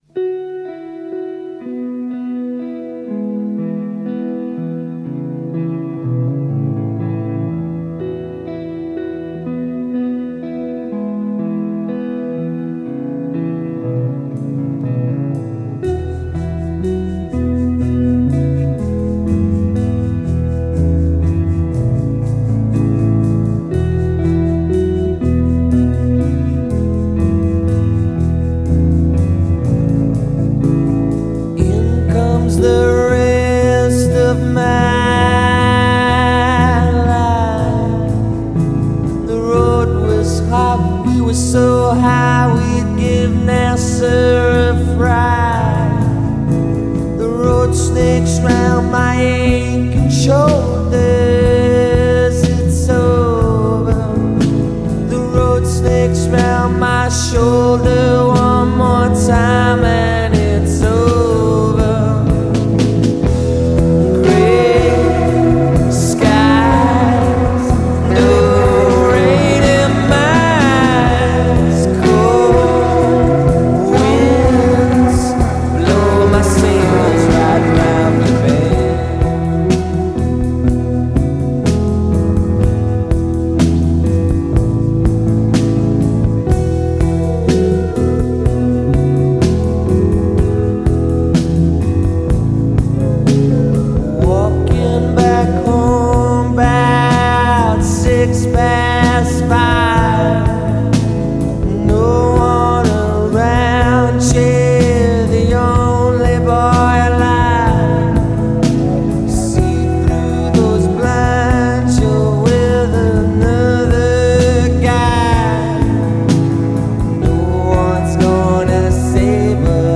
Alternative Rock
Indie Rock